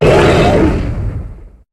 Cri de Némélios dans Pokémon HOME.